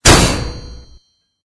CHQ_FACT_stomper_small.ogg